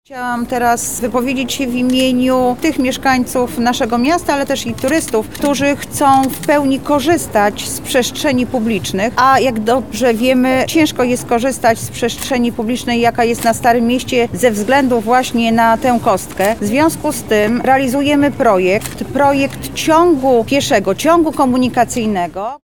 – mówi Anna Augustyniak, Zastępczyni Prezydenta Miasta ds. społecznych